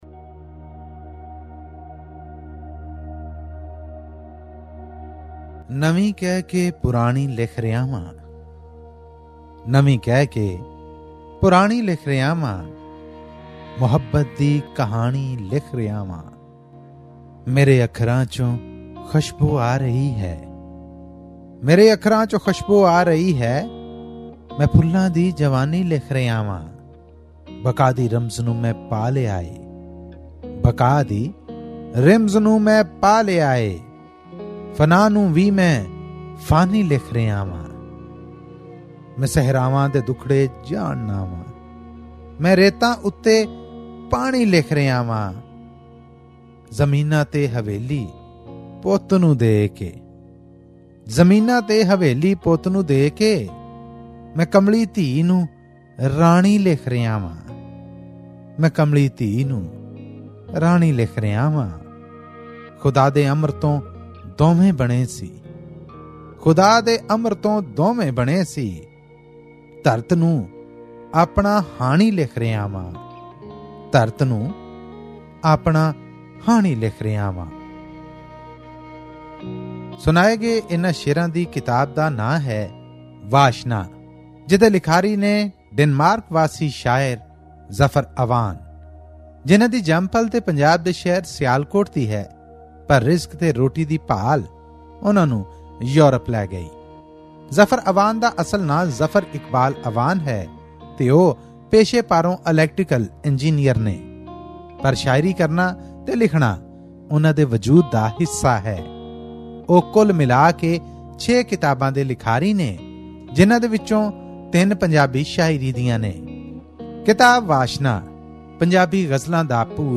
Pakistani Punjabi poetry book review